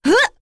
Seria-Vox_Jump.wav